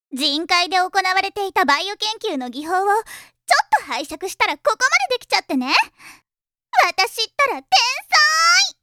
ボイス
性別：女